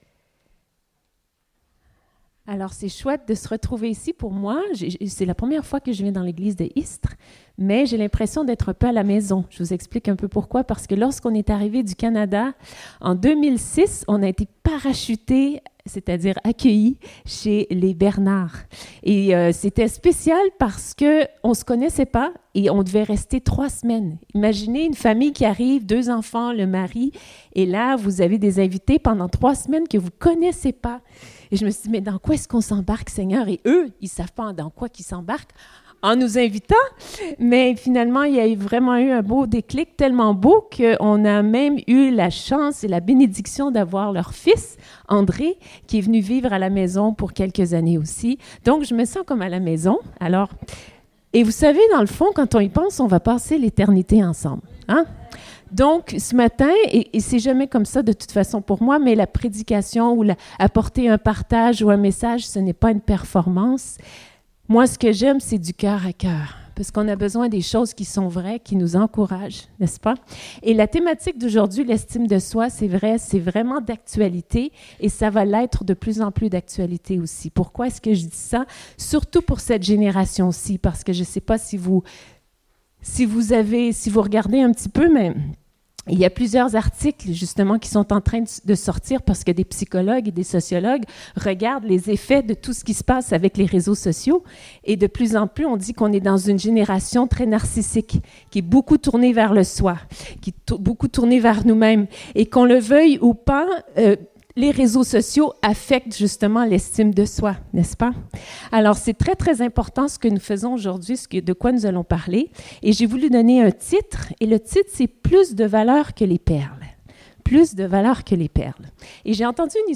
Date : 18 mai 2019 (Culte Dominical)